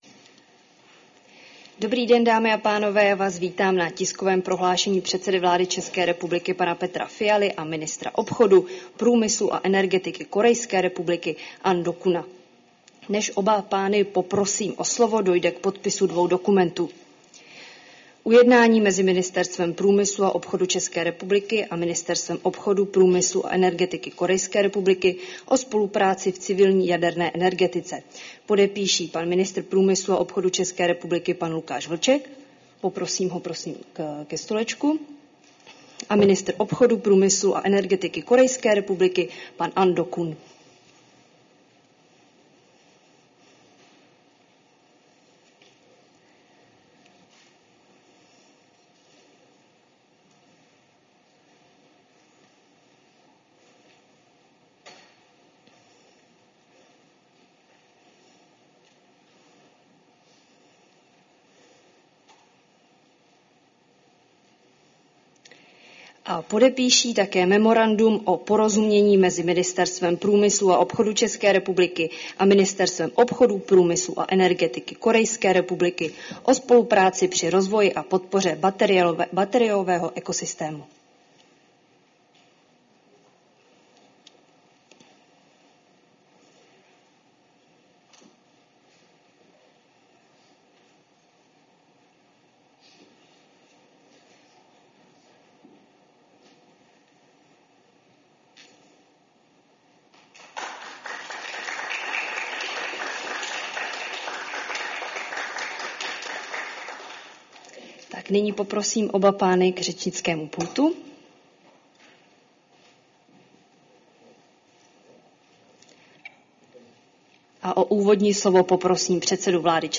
Společné prohlášení premiéra Petra Fialy a korejského ministra obchodu průmyslu a energetiky Ahn Dukgeuna